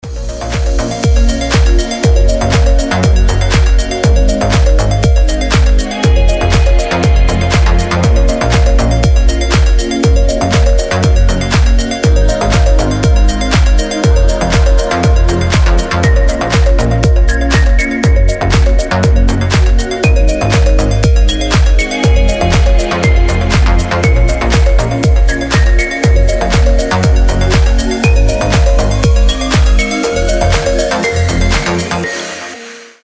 • Качество: 320, Stereo
deep house
мелодичные
женский голос
Красивый и мелодичный Deep House.